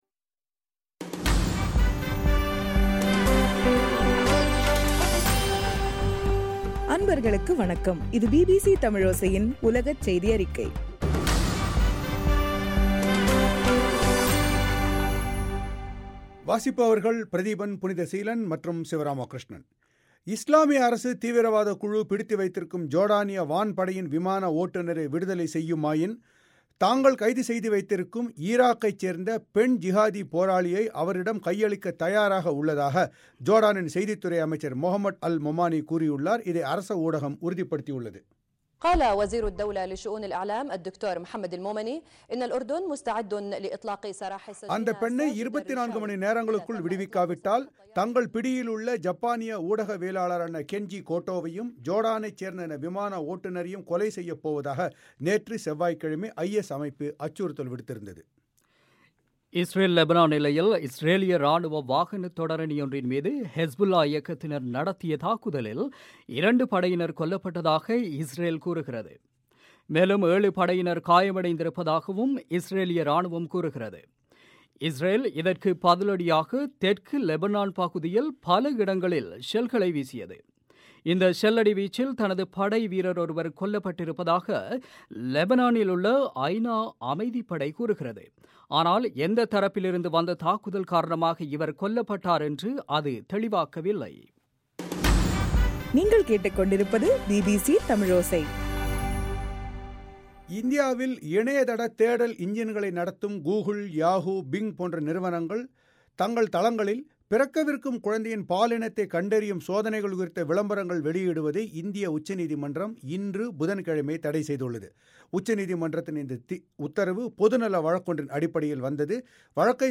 ஜனவரி 28 2015 பிபிசி தமிழோசையின் உலகச் செய்திகள்